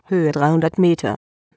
Dazu habe ich mir 100m - 200m - 300m... als Sprachansage bei AT&T erzeugt und die Alarme mit x>100m ... gesetzt.
Wenn du in dem AT&T Link die deutsche Damenstimme nimmst, klingt sie sehr ähnlich.